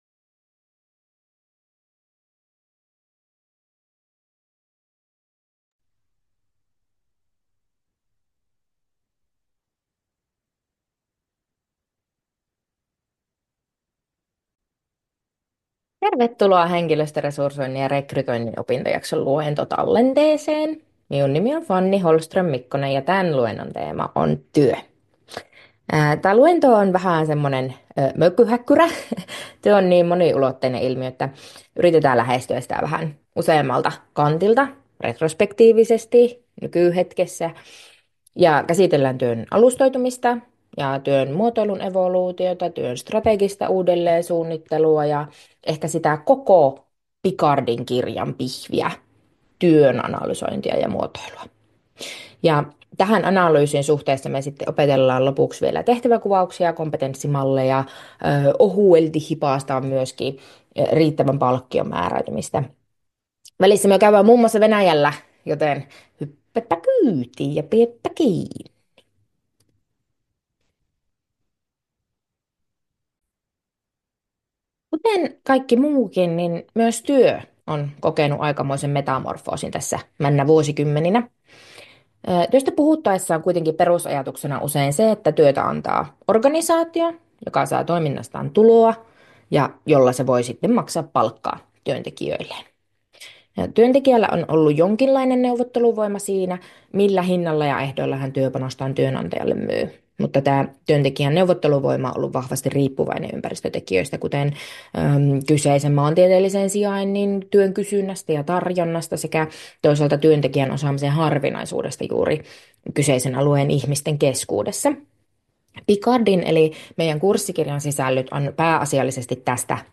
YJOA2220 Henkilöstöresursointi ja rekrytointi, luentotallenne aiheesta työ.